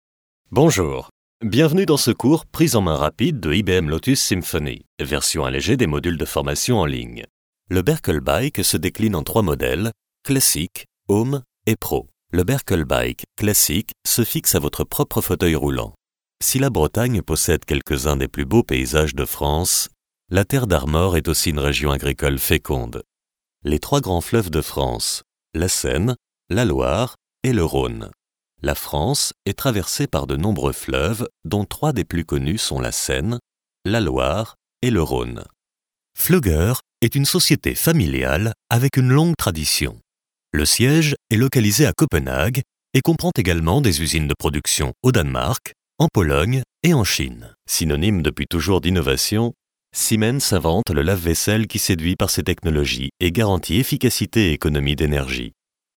Sprechprobe: eLearning (Muttersprache):
A chameleon voice, warm and bass.